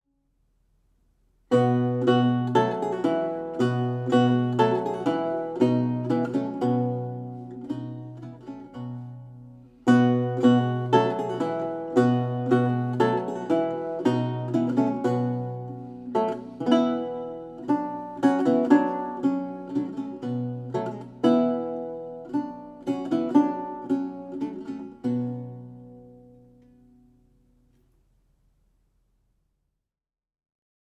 Audio recording of a lute piece